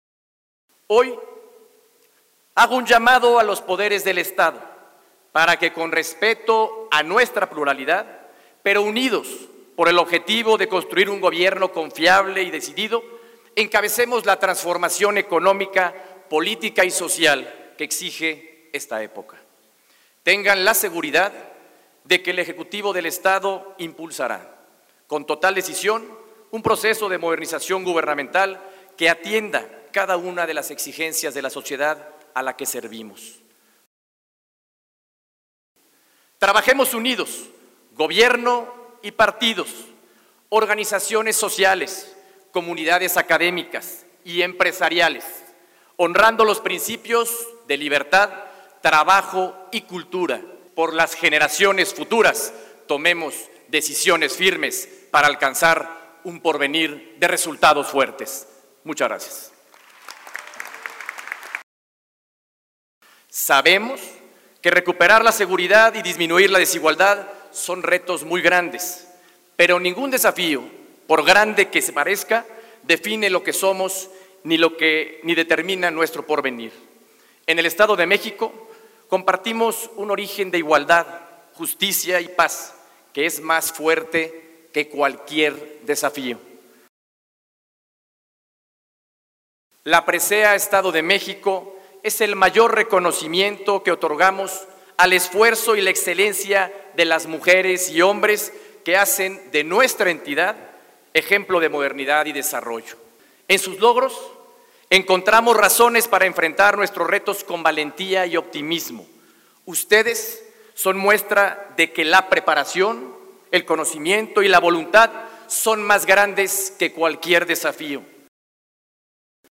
Encabeza Gobernador mexiquense conmemoración por el 194 Aniversario de la Fundación del Estado de México
En el Teatro Morelos, al que asistieron líderes de los diferentes sectores sociales de la entidad, empresarios, académicos, asociaciones religiosas, activistas sociales, de gremios sindicales y laborales y grupos étnicos, entre otros,  el Gobernador aseguró que la entidad cuenta con instituciones que brindan  estabilidad para enfrentar cualquier adversidad.